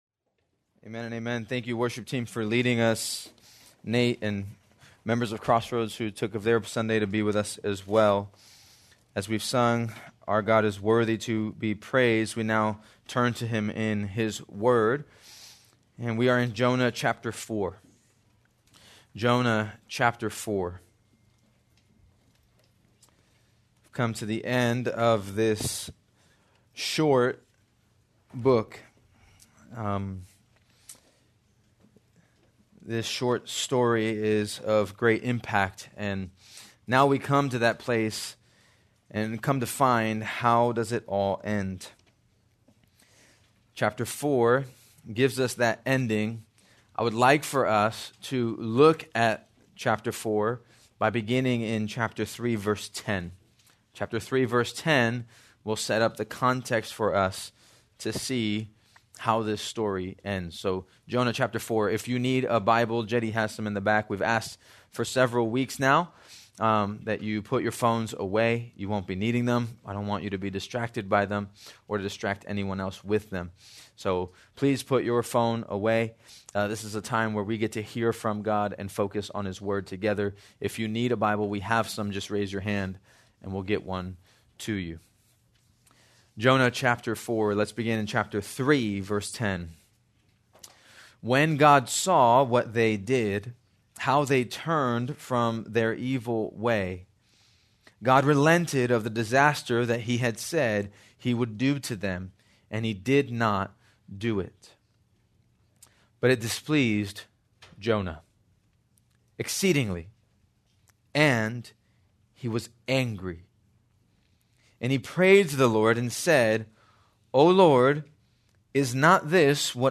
April 20, 2025 - Sermon